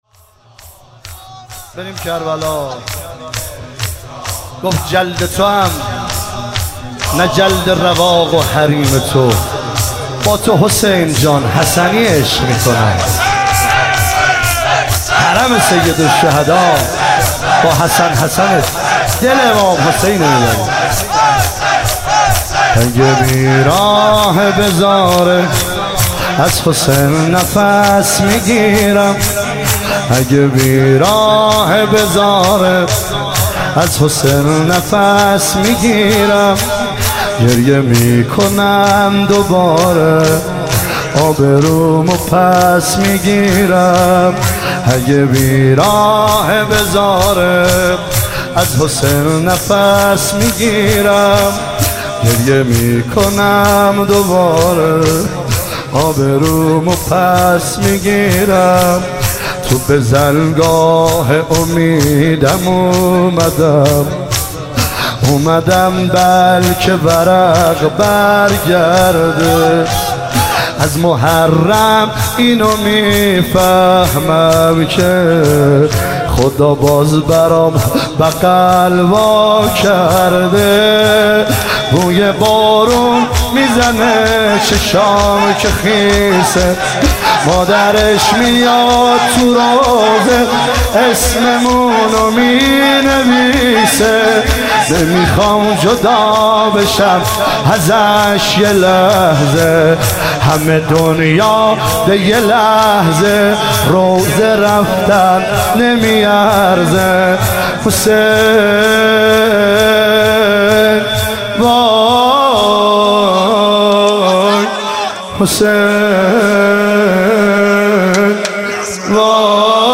عنوان شب بیست و دوم محرم الحرام ۱۳۹۸
شور